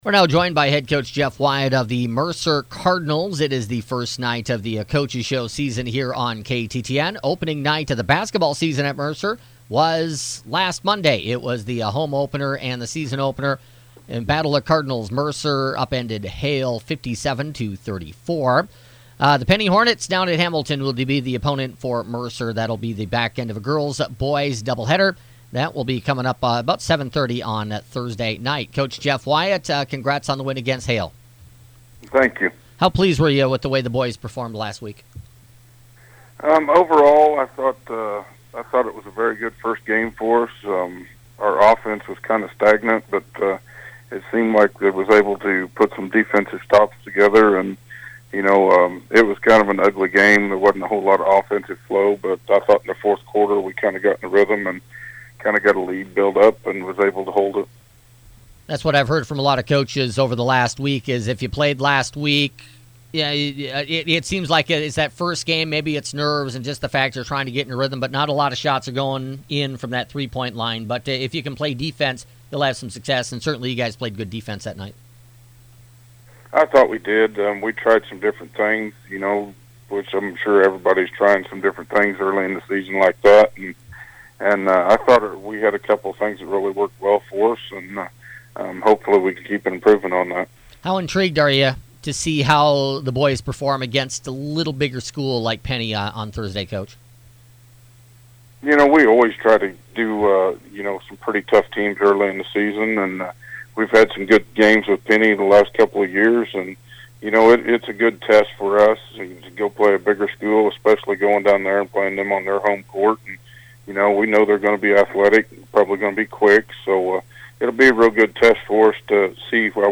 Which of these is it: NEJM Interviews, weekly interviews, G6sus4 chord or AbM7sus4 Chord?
weekly interviews